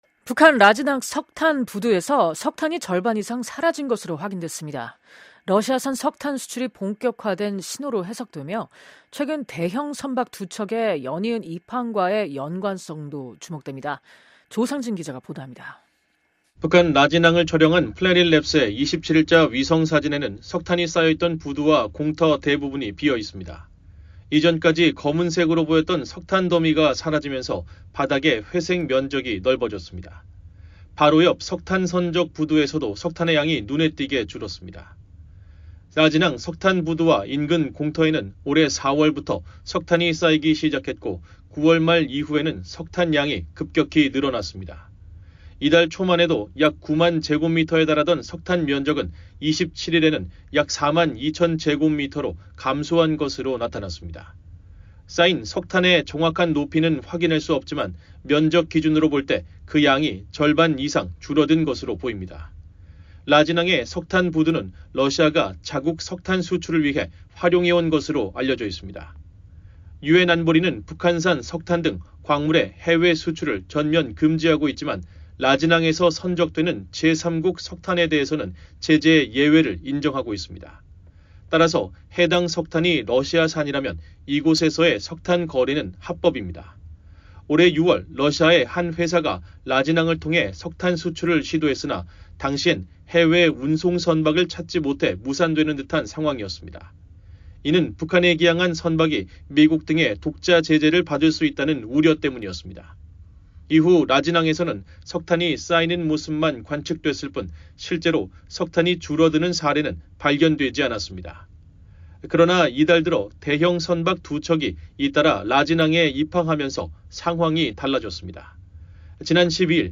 기자가 보도합니다.